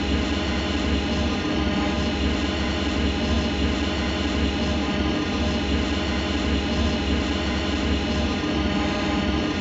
t47_fly2.wav